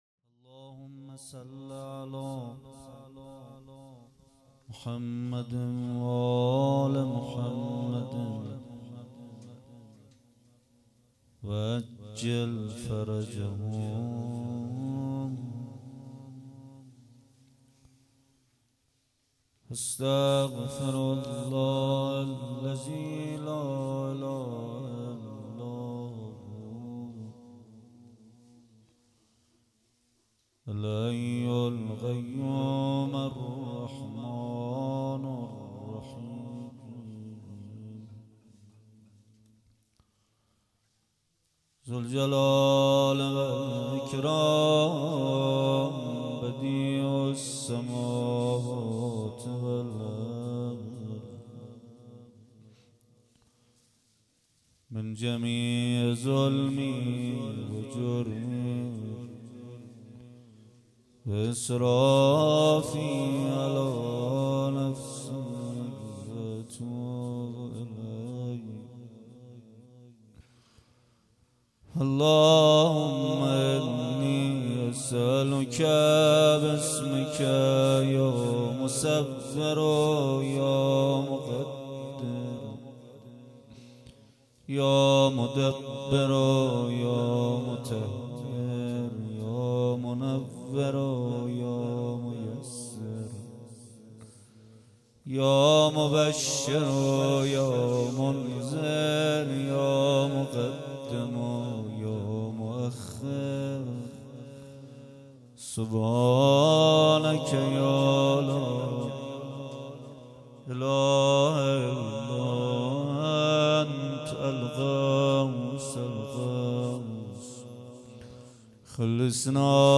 مراسم شب هشتم مناجات‌خوانی ماه رمضان هیأت ریحانةالنبی
ابتدا به دعایخوانی پرداخت و سپس شعری را زمزمه کرد.